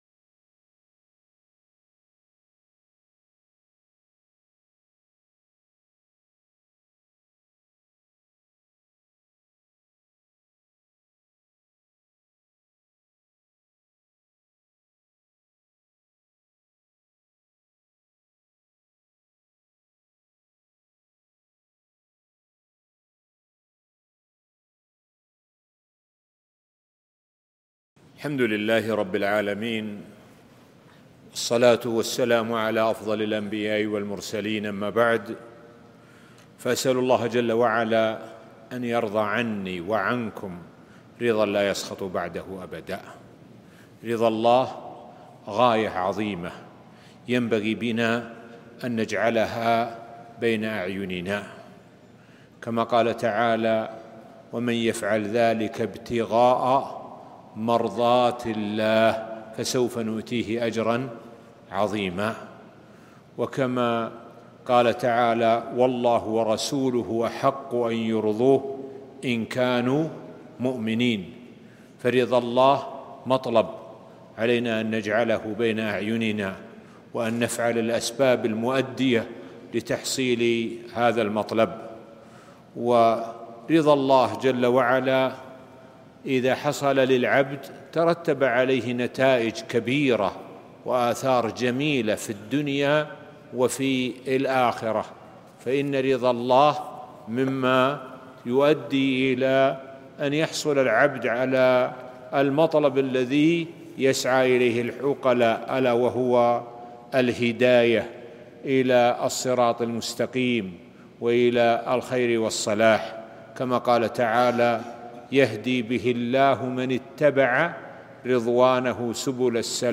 محاضرة - كيف يرضى الله عنك؟